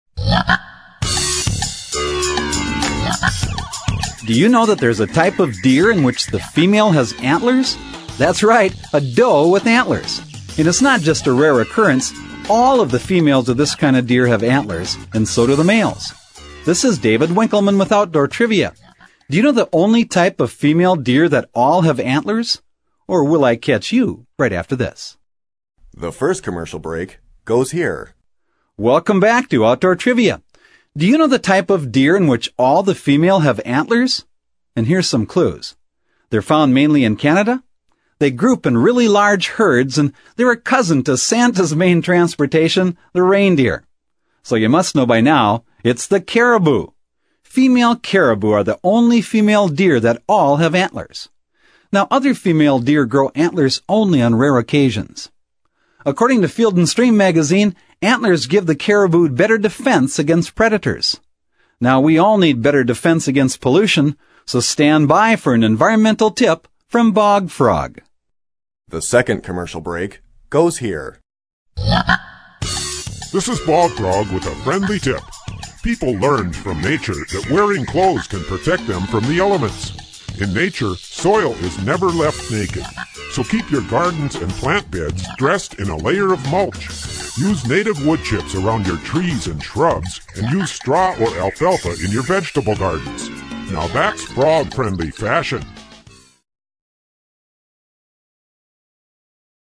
In fact, the question and answer trivia format of this program remains for many people, a most enjoyable, yet practical method of learning.
Programs are 2 1/2 minutes long, including commercial time. Bog Frog’s Tips conclude each program.
Bog Frog's voice is distinctive and memorable, while his messages remain positive and practical, giving consumers a meaningful symbol to remember.